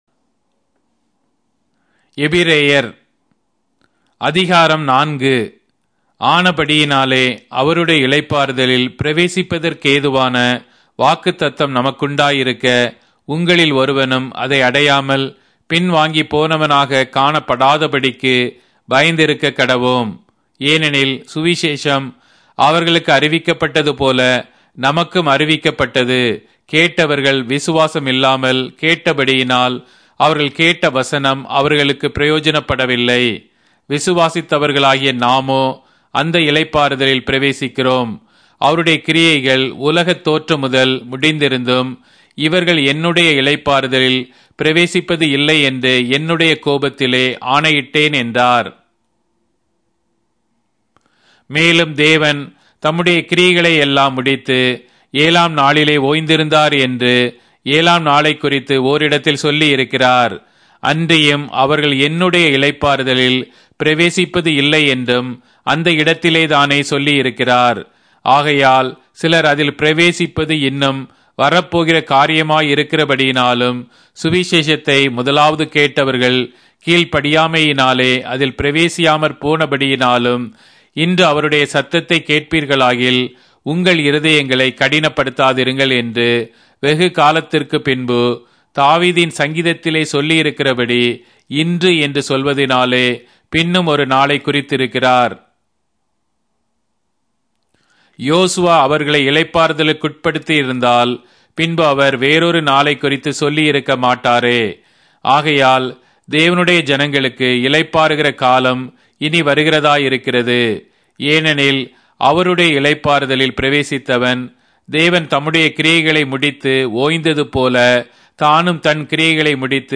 Tamil Audio Bible - Hebrews 2 in Irvor bible version